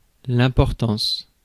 Prononciation
Prononciation France: IPA: [ɛ̃.pɔʁ.tɑ̃s] Accent inconnu: IPA: /ɛ̃ pɔʁ tɑ̃s/ Le mot recherché trouvé avec ces langues de source: français Traduction 1.